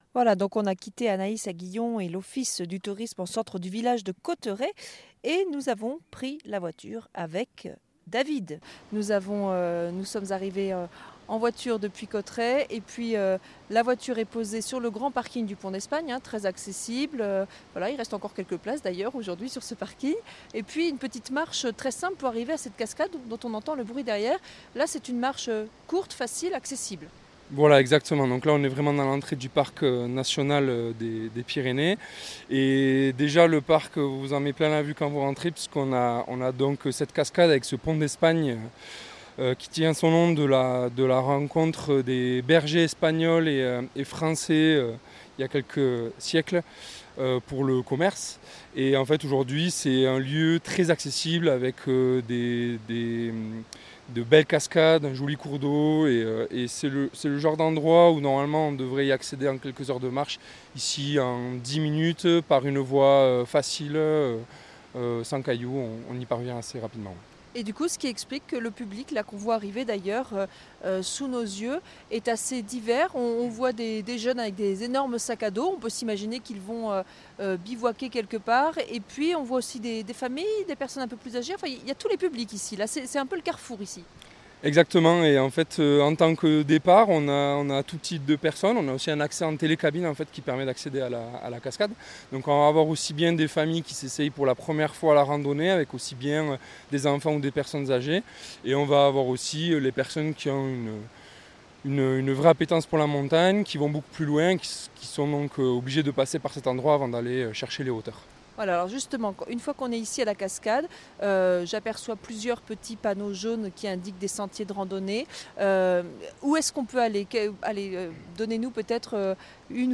Interview et reportage